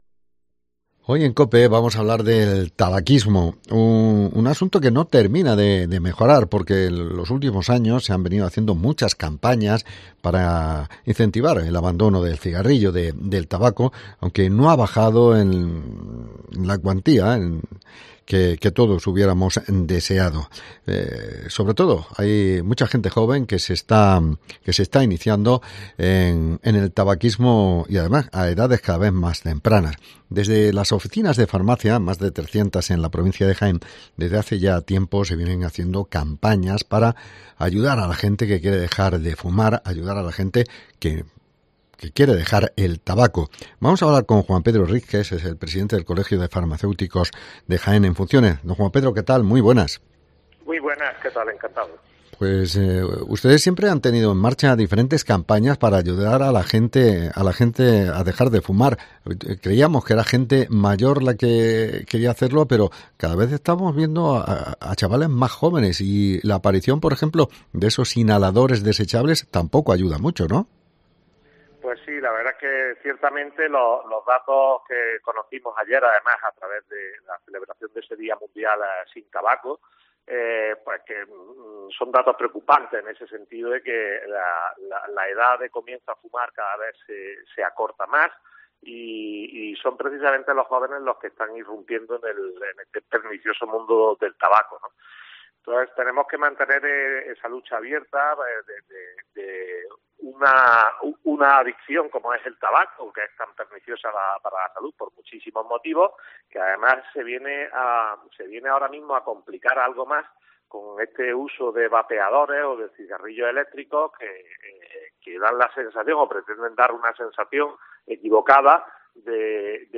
Dejar de Fumar es posible. Entrevista